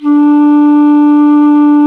Index of /90_sSampleCDs/Roland LCDP04 Orchestral Winds/FLT_Alto Flute/FLT_A.Flt nv 3
FLT ALTO F05.wav